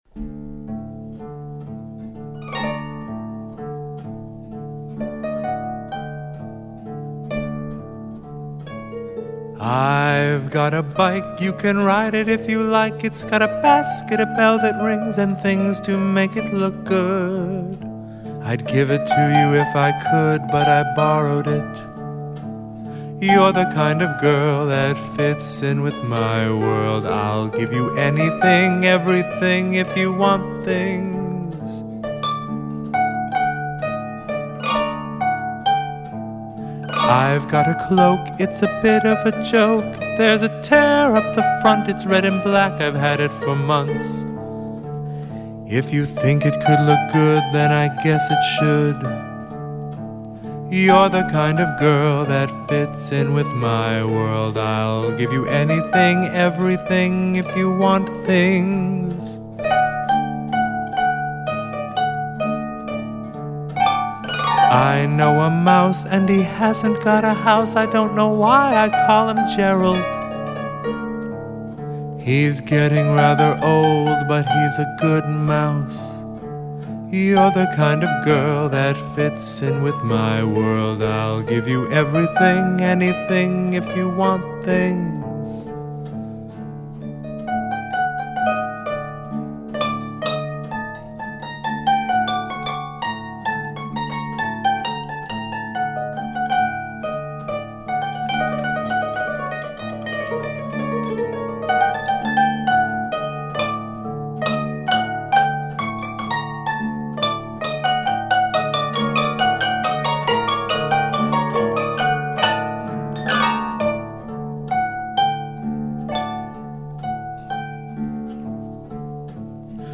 arranged and performed with harpist